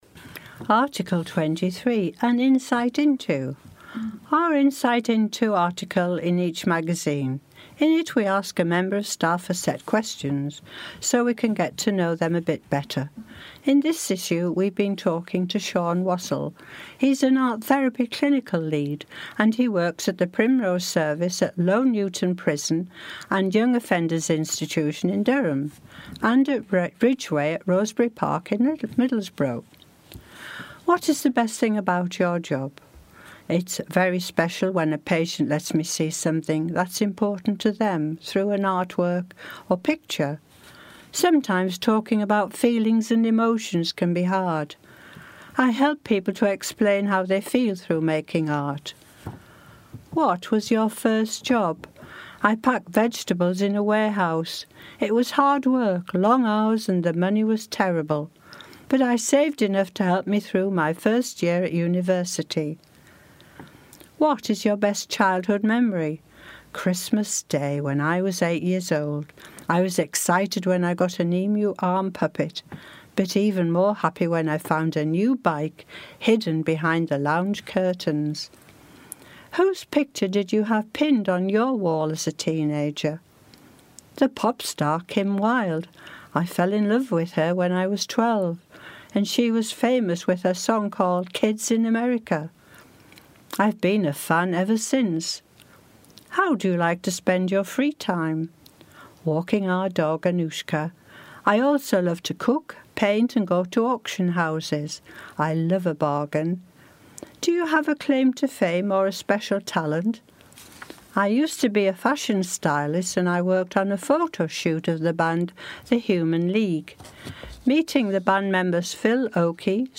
In it we ask a member of staff a set of questions so we can get to know them a bit better.